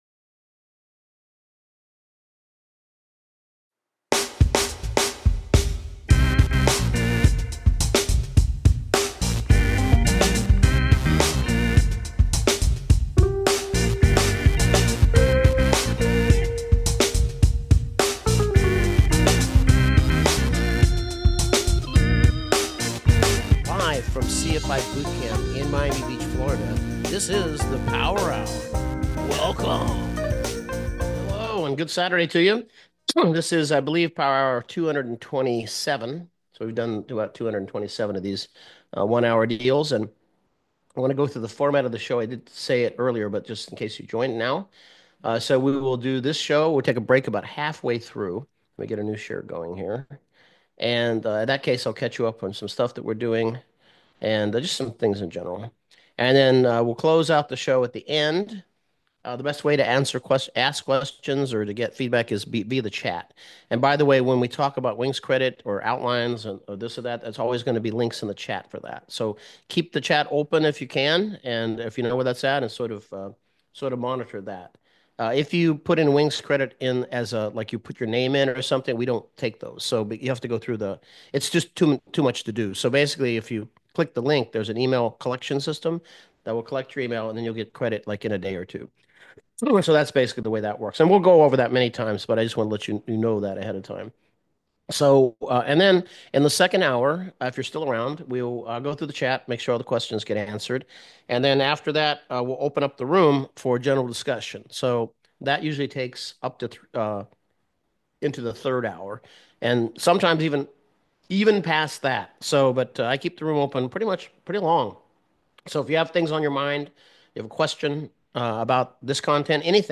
Entire Ground Lesson on Inoperative Equipment.